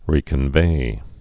(rēkən-vā)